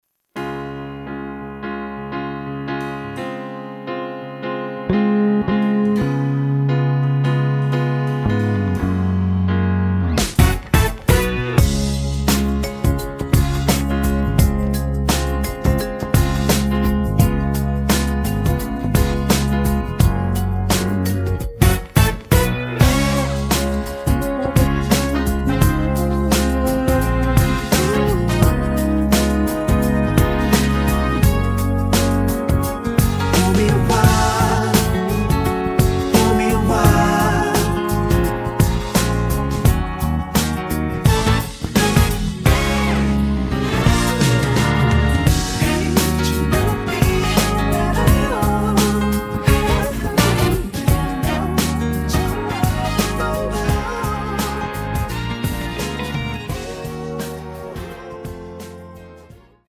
음정 원키
장르 가요 구분